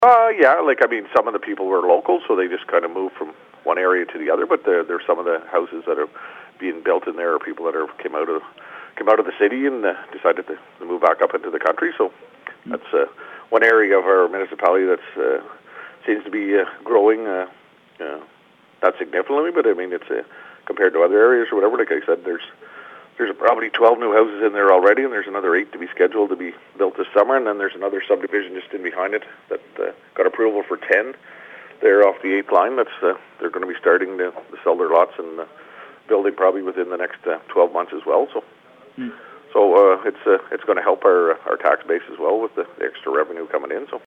Clarendon Mayor Ed Walsh talk to CHIP 101.9 about the municipality’s budget for 2022, which passed in January.